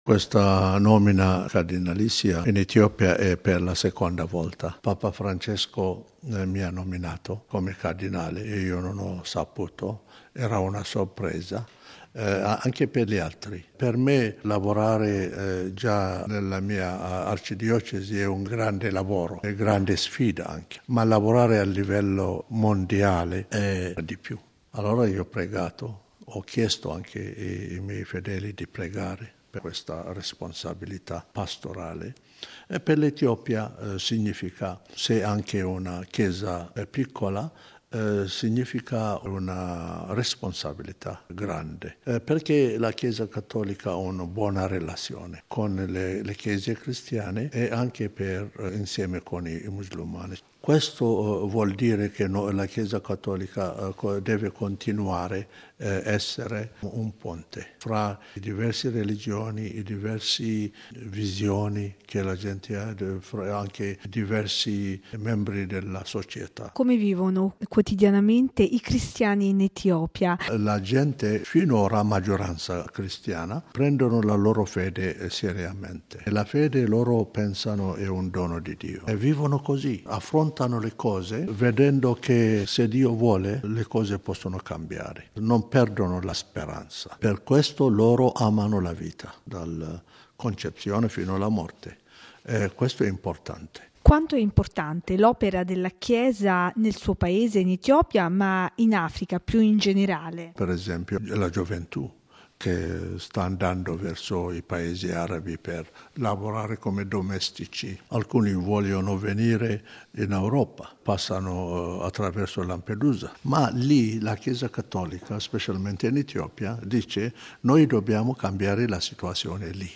Ascoltiamo il suo commento